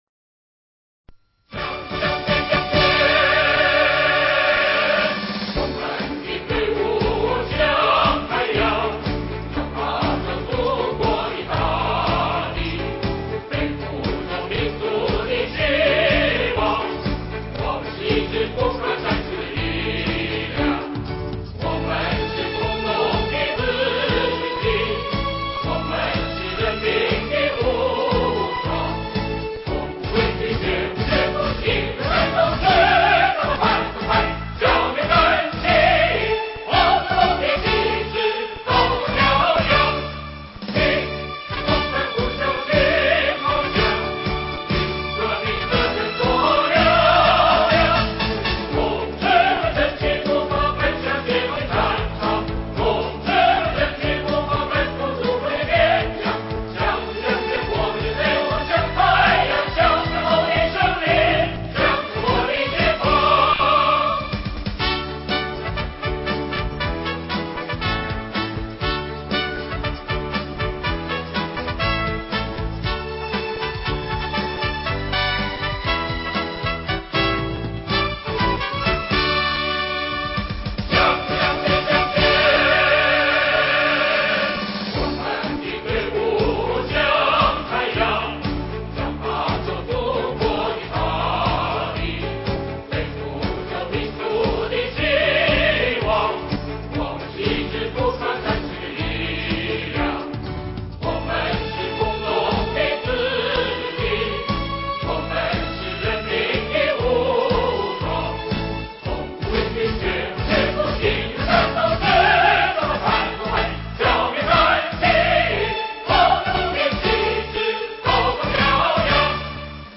人文学院大合唱